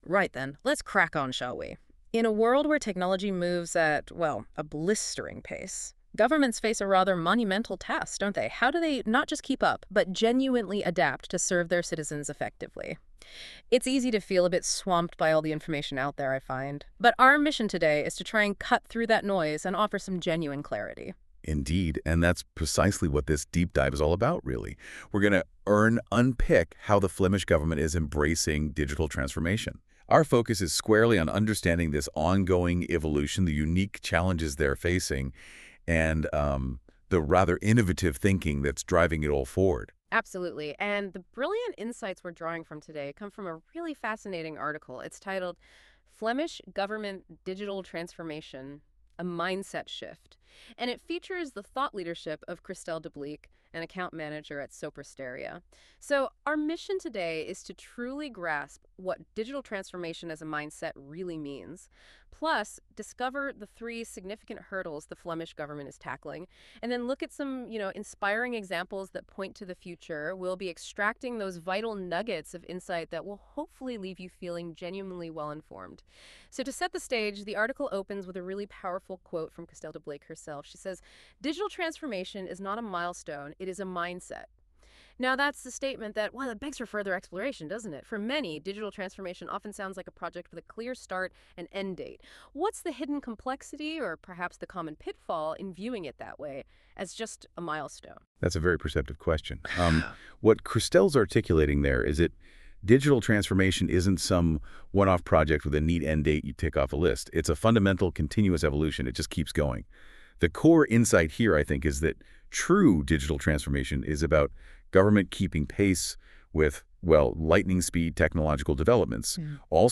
In this short conversation, two of our Sopra Steria colleagues reflect on the key themes that matter: digital trust, inclusion, AI with purpose, and why transformation is really about people.